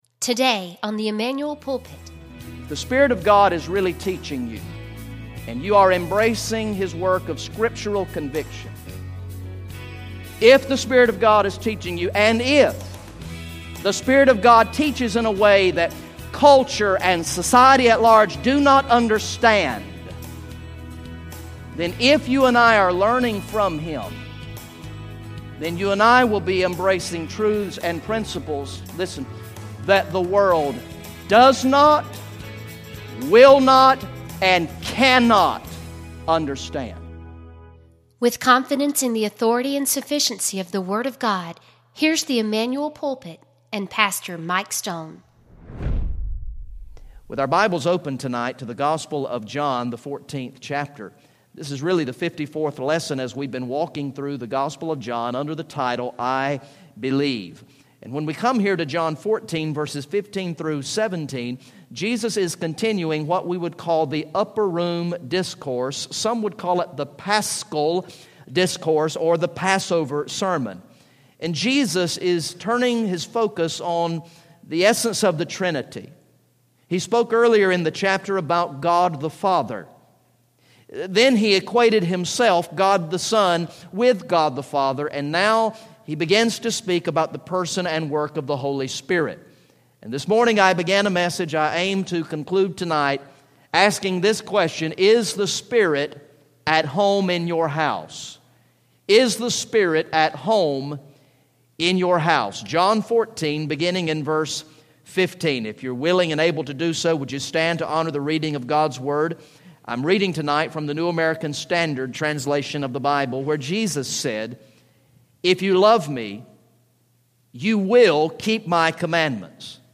Message #54 from the sermon series through the gospel of John entitled "I Believe" Recorded in the evening worship service on Sunday, January 10, 2016